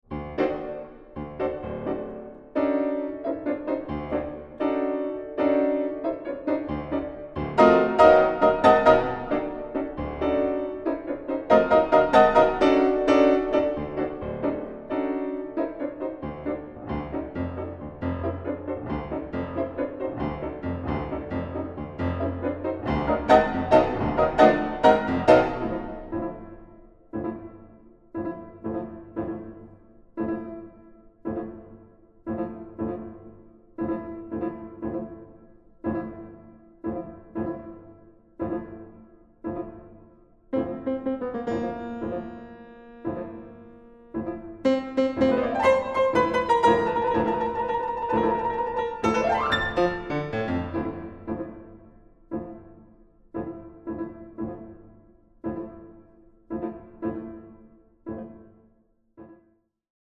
Piano
Recording: Mendelssohnsaal, Gewandhaus Leipzig